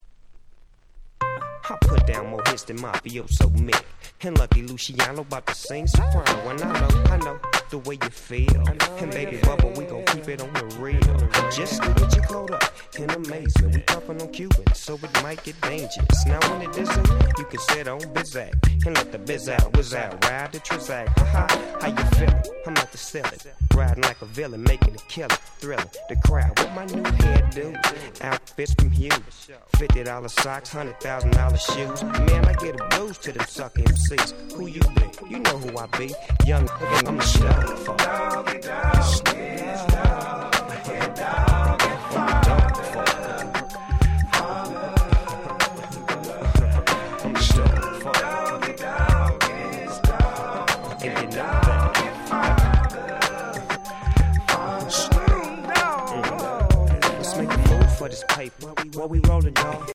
97' Nice West Coast Hip Hop !!